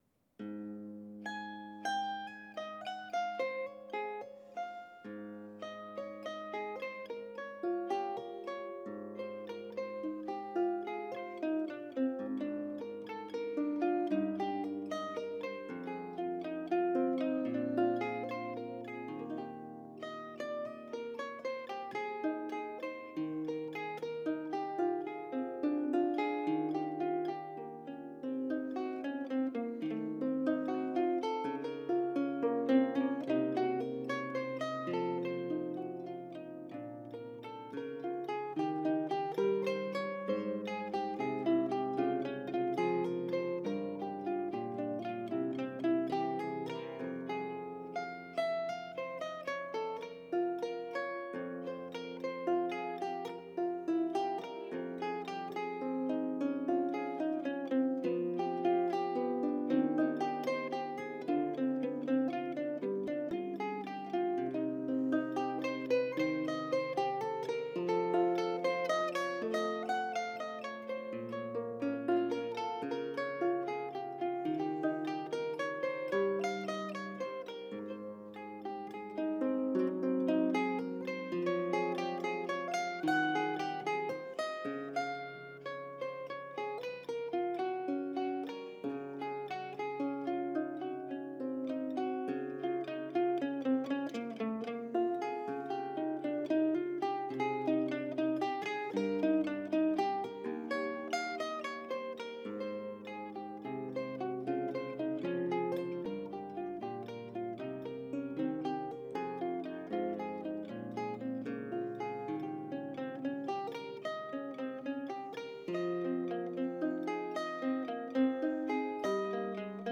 Johann Sebastian Bach arrangiert für die Zither
Zither
Juli 2022 in der Hochschule für Musik und Theater München |